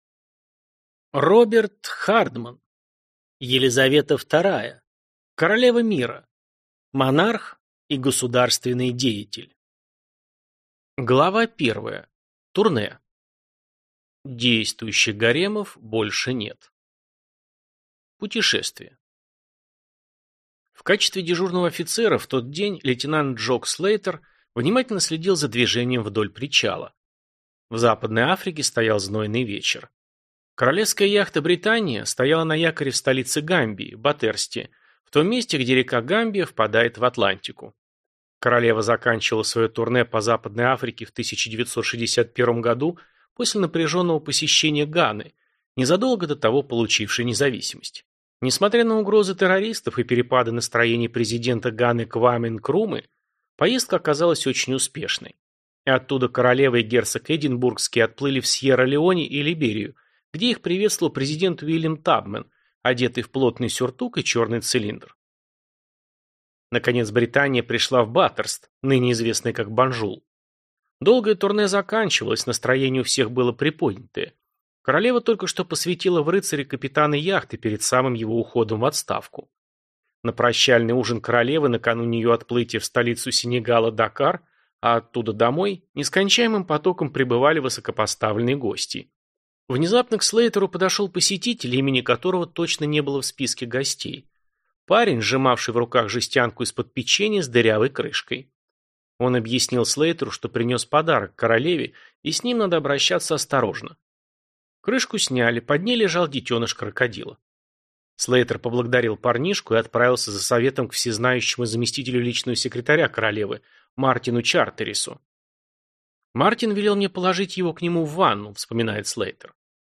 Аудиокнига Елизавета II. Королева мира. Монарх и государственный деятель | Библиотека аудиокниг